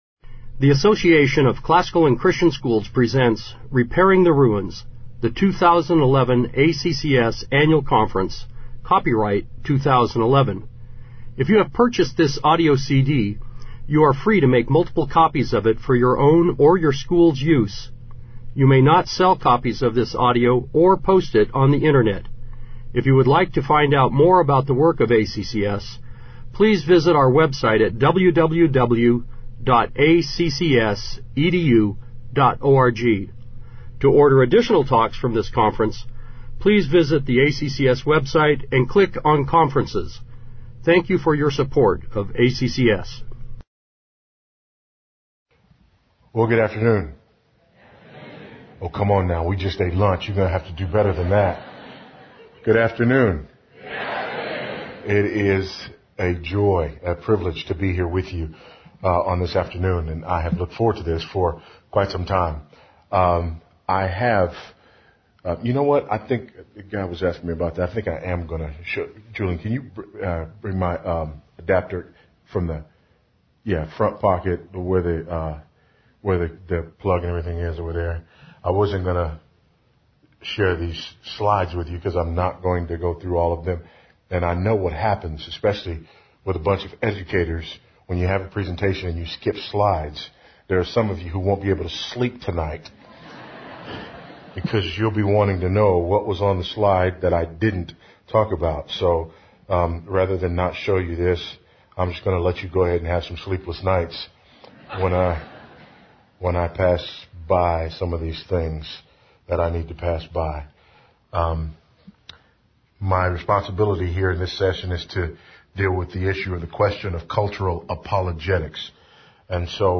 2011 Foundations Talk | 0:52:32 | All Grade Levels, Culture & Faith
The Association of Classical & Christian Schools presents Repairing the Ruins, the ACCS annual conference, copyright ACCS.